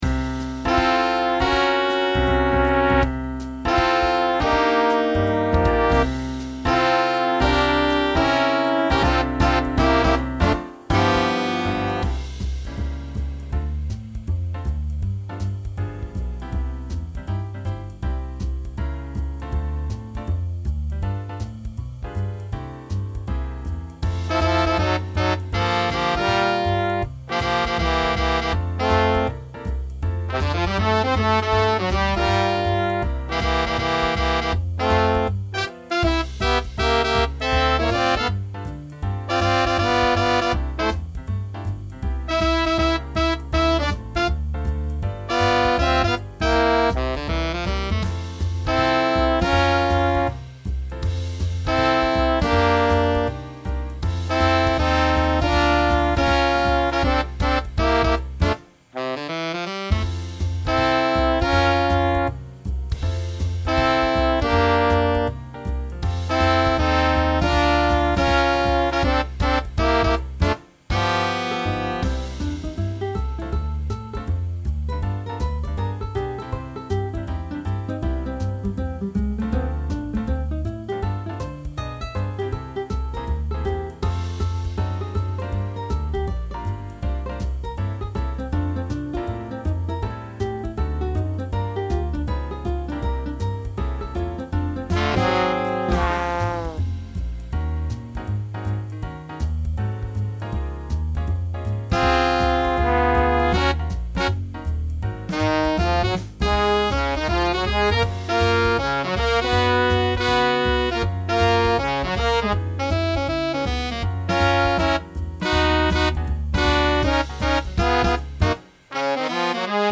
Medium or up swing. Features Tenor sax solo.